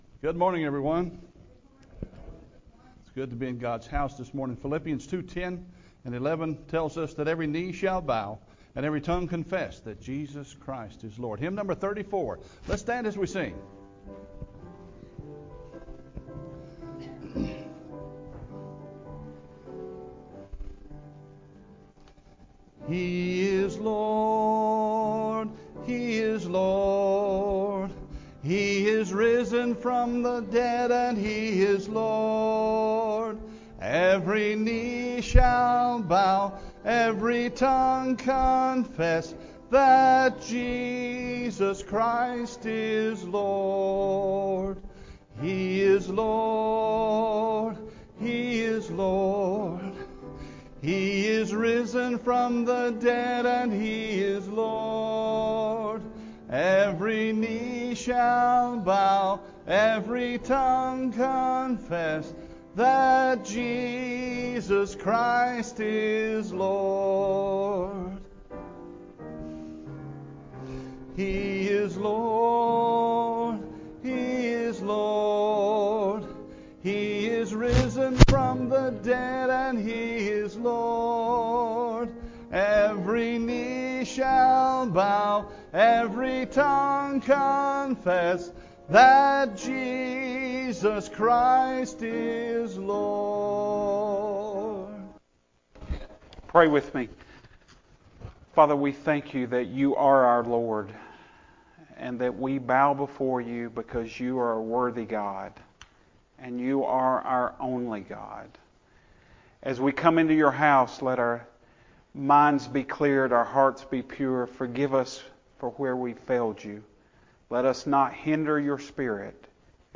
Full Service Audio